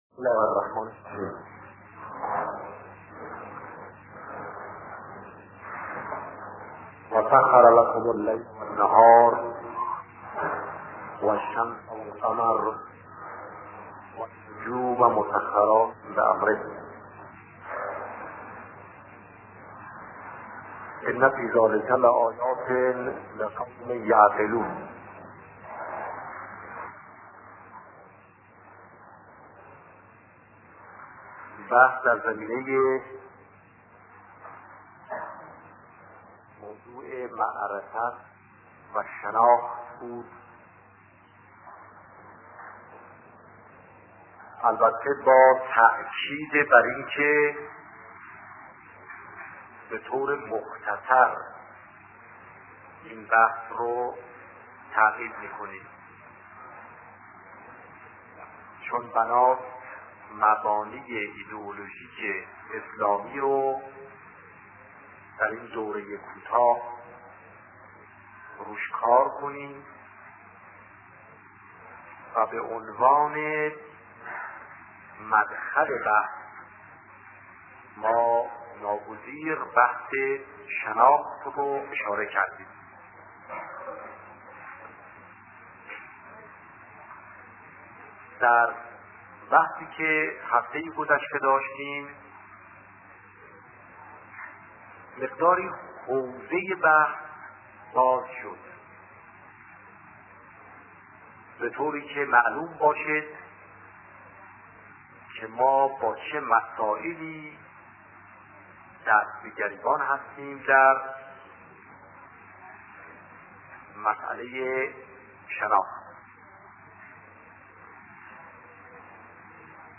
صوت سخنرانی شهید باهنر- در خصوص مبانی ایدئولوژیک اسلامی